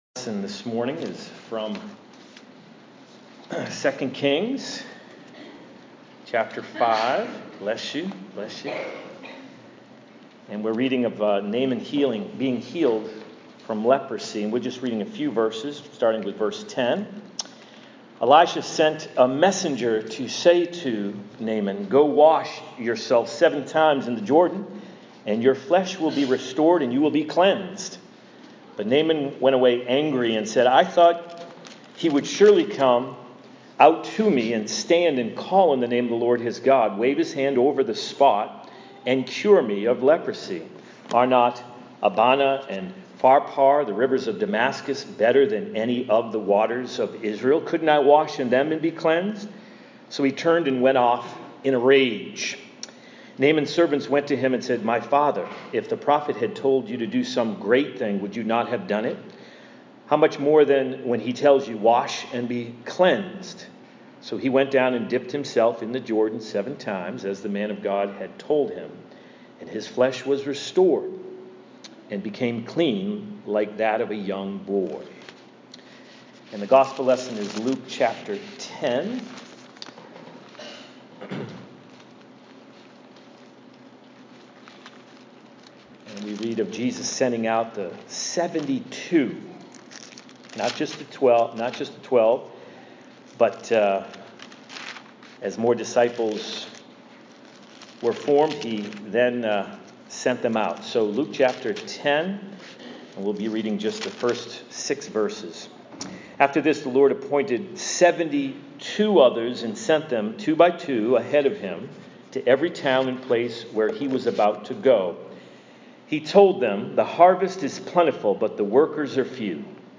Sermon_-The-Harvest-is-Plentiful-But-the-Workers-are-Few.mp3